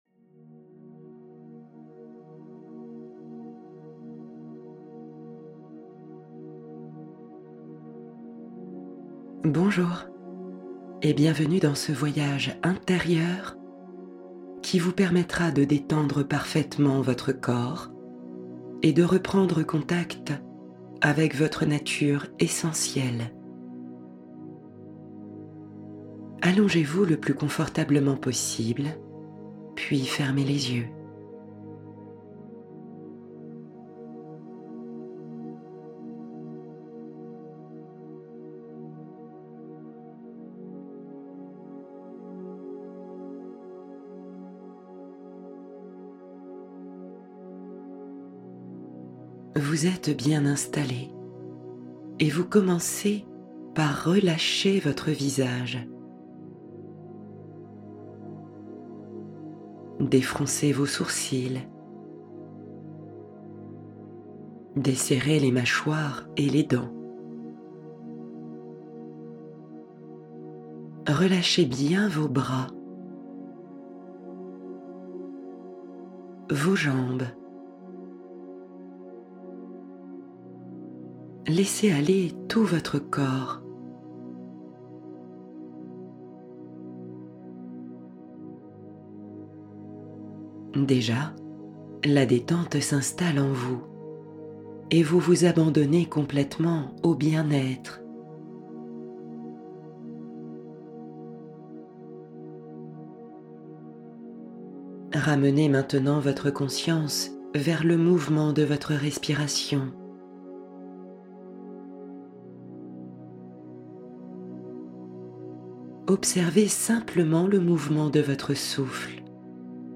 Guérison de l'âme - Méditation guidée